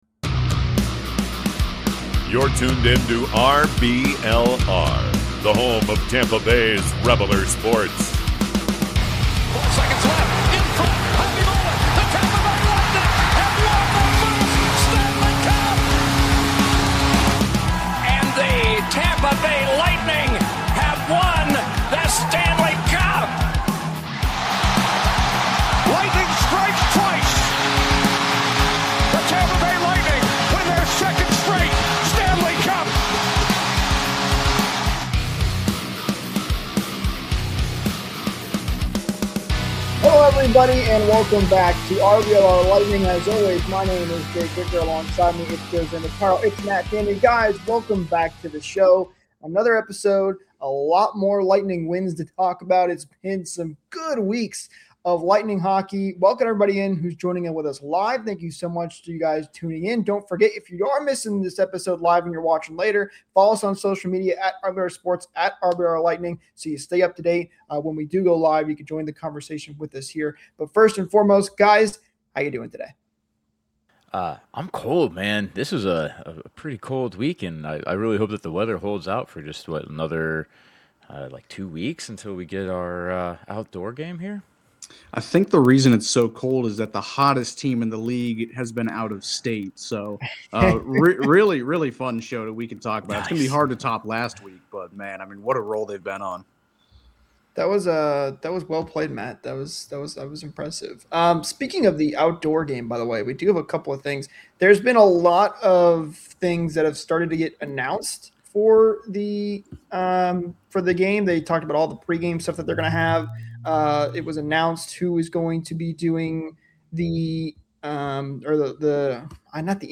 The crew sat down to recap an incredible run and explain why there’s no reason to panic despite the loss. If you missed the live show, catch the full replay now!